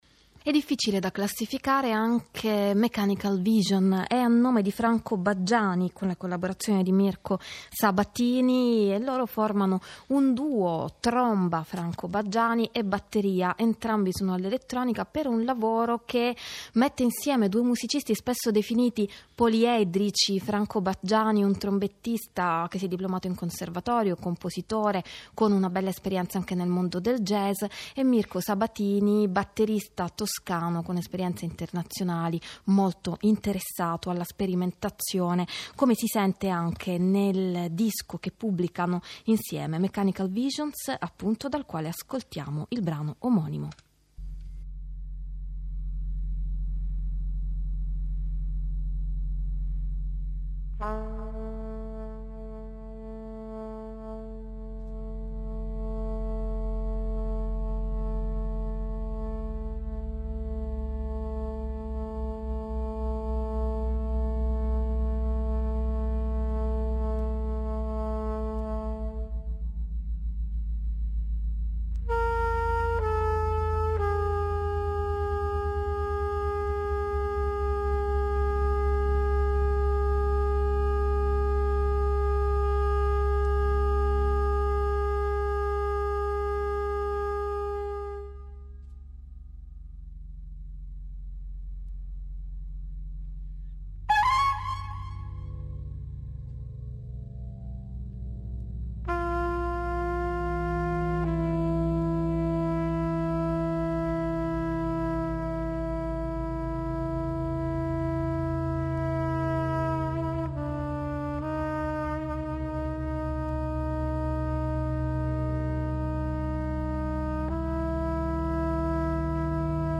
batteria